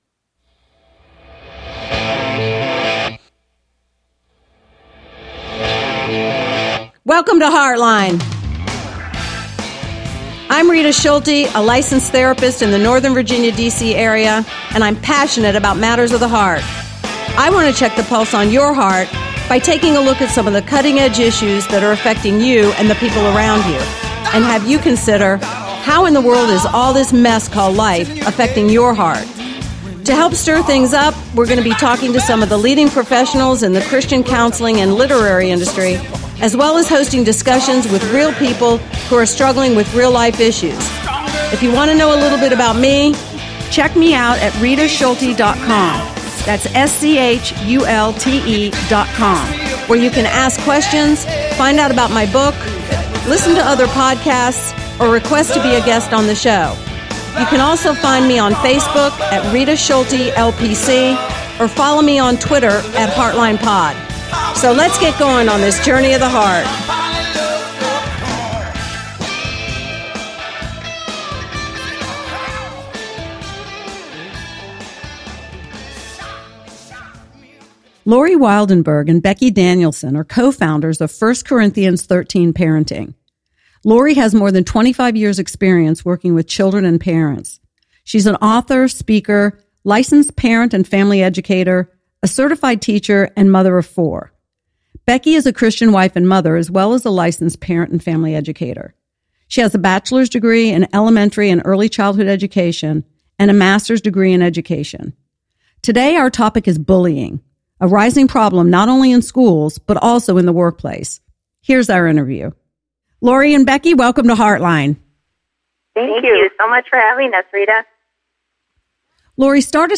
Today our topic is bullying-- a rising problem not only in schools but also in the workplace. Here’s our interview: